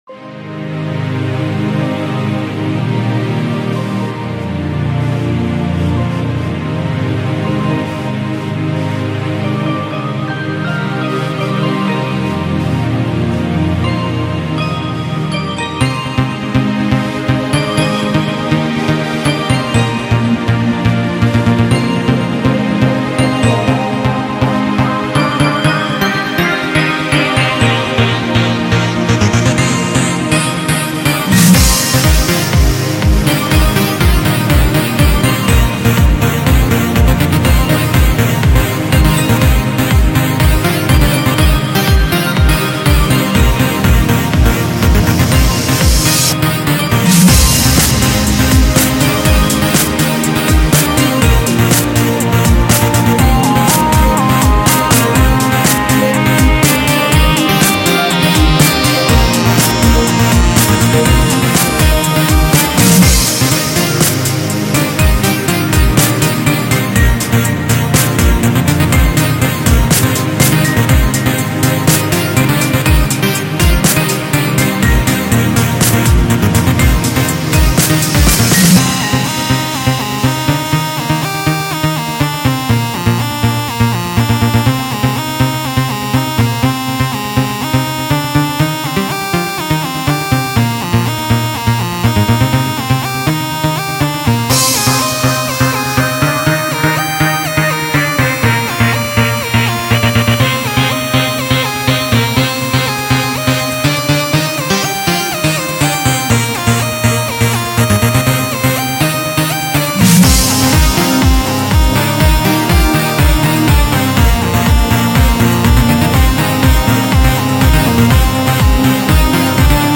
Tempo: 122 bpm Genre: Trance Wanna remix?